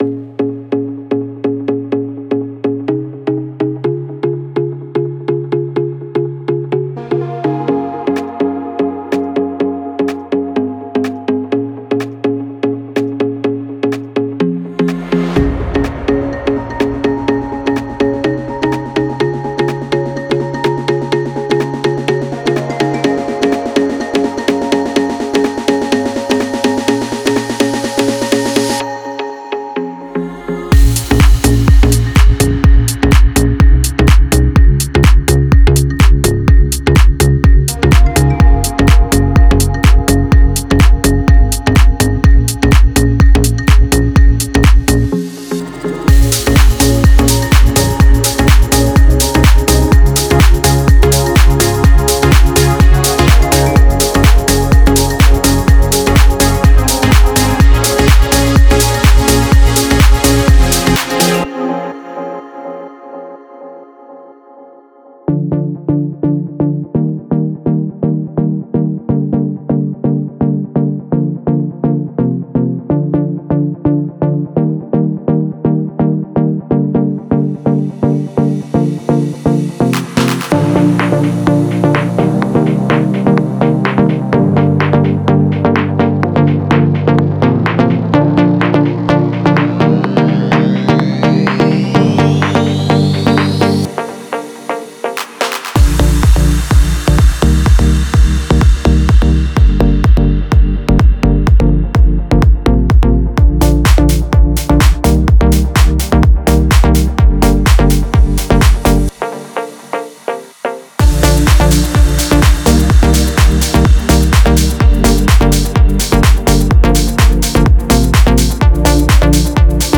Genre:EDM
デモサウンドはコチラ↓